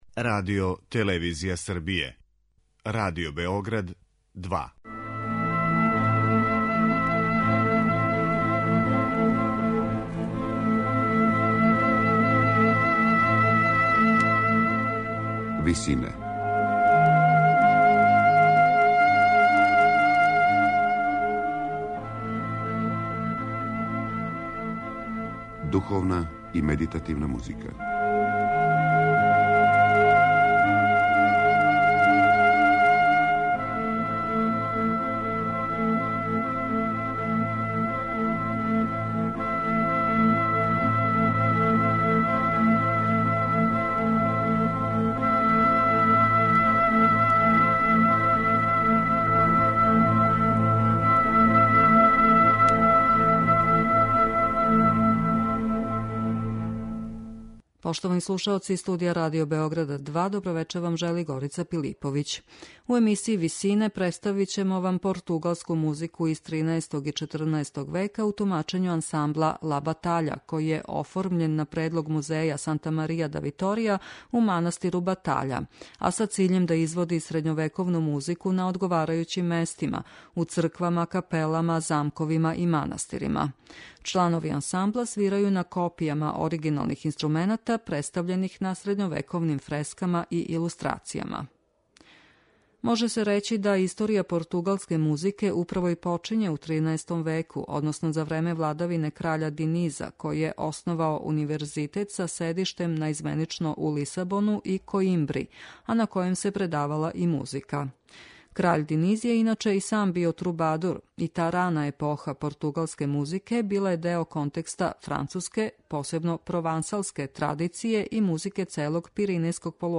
Ансамбл 'Ла батаља'
У емисији Висинe слушаћемо португалску музику XIII и XIV века у извођењу ансамбла Ла батаља.